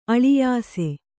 ♪ aḷiyāse